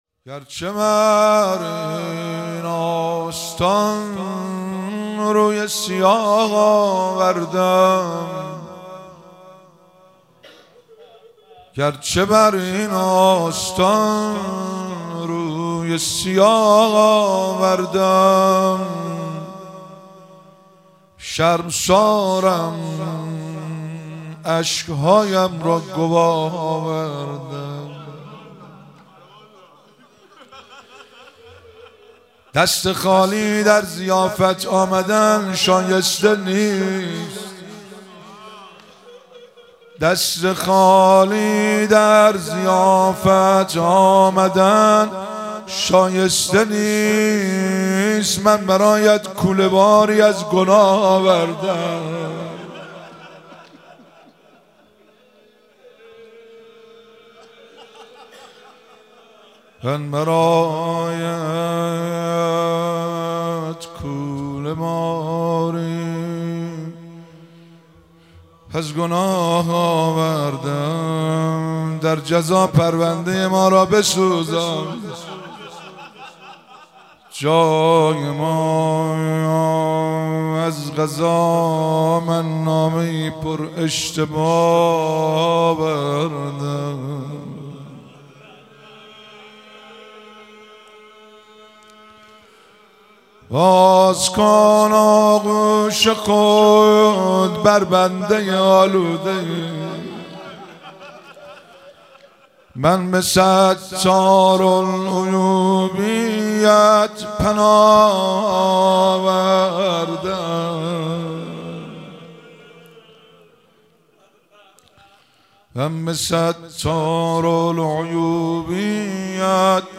مراسم مناجات خوانی شب چهارم ماه رمضان 1444